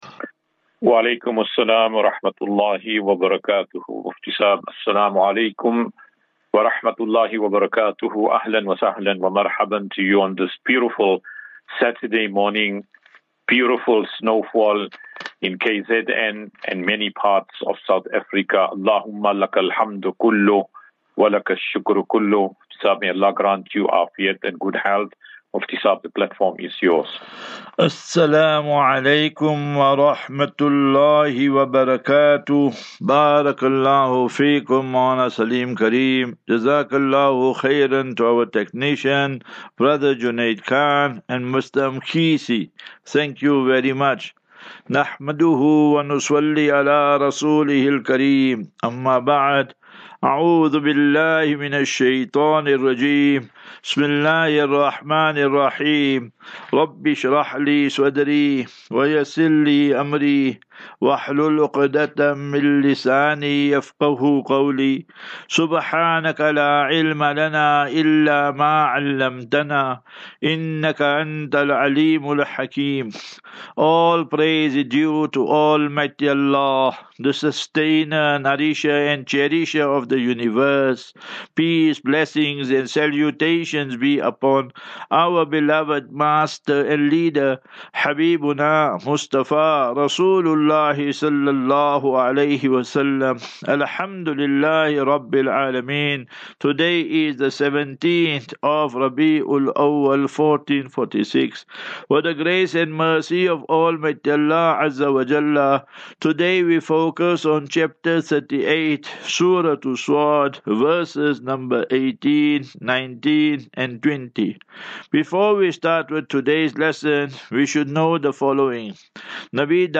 QnA
Daily Naseeha.